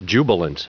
Prononciation du mot jubilant en anglais (fichier audio)
Prononciation du mot : jubilant